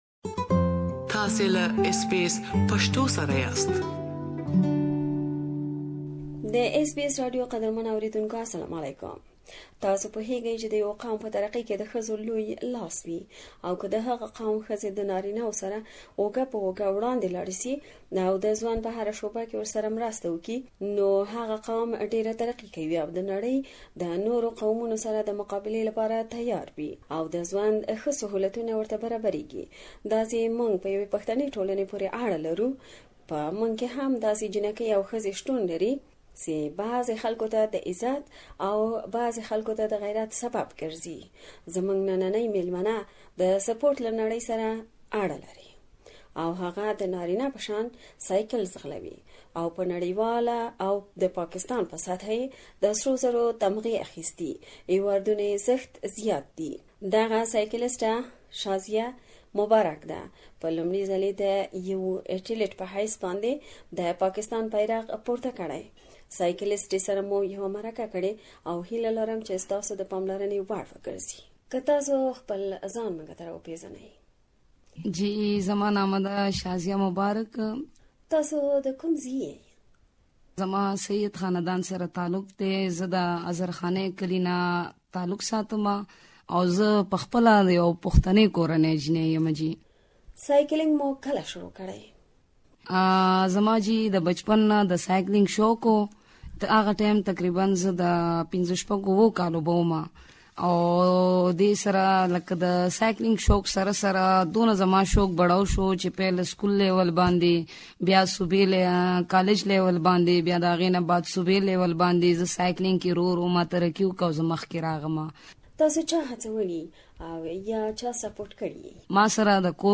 We have interviewed her about her achievements.